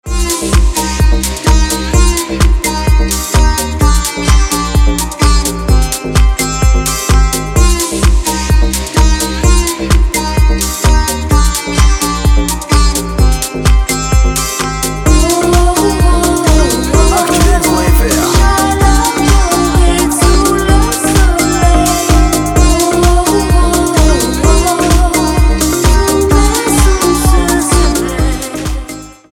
поп
мелодичные
dance
попса